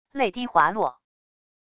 泪滴滑落wav音频